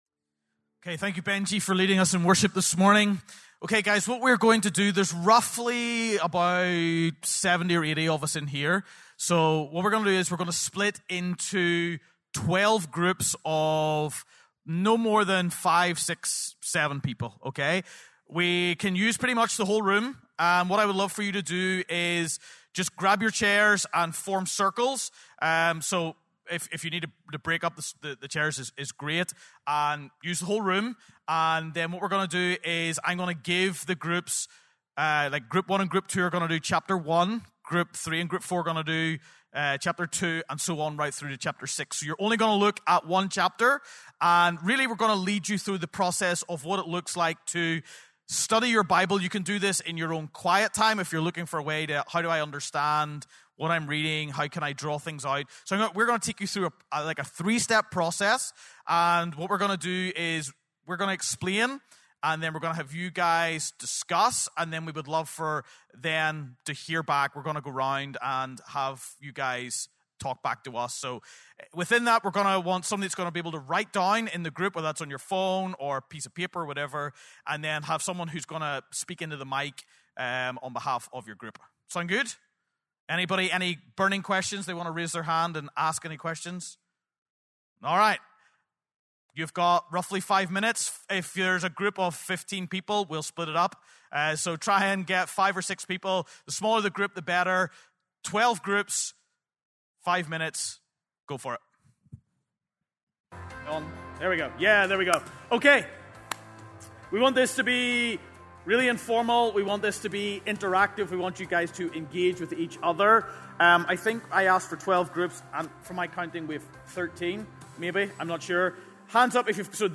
November 9, 2025 - Sermon